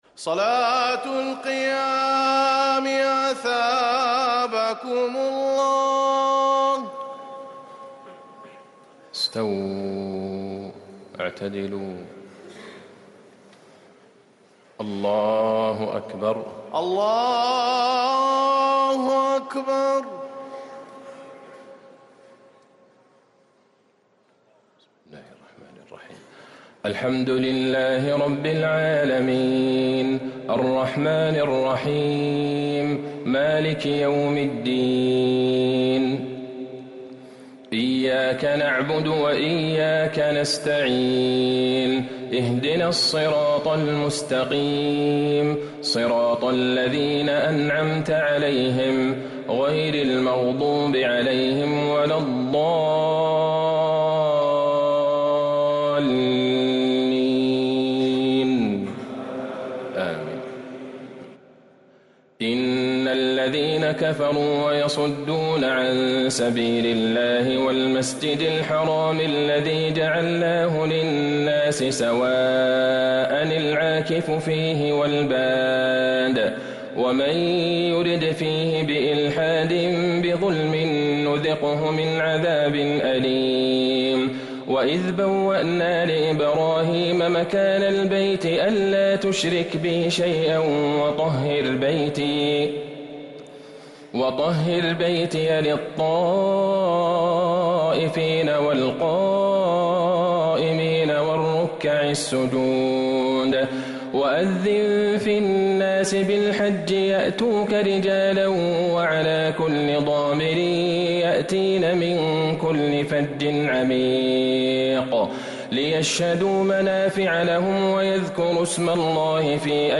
تراويح ليلة 22 رمضان 1444هـ من سورتي الحج (25_78)والمؤمنون (1-22)| Taraweeh 22 th night Ramadan 1444H surah AlHajj + al-Mu'minun > تراويح الحرم النبوي عام 1444 🕌 > التراويح - تلاوات الحرمين